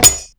Shield2.wav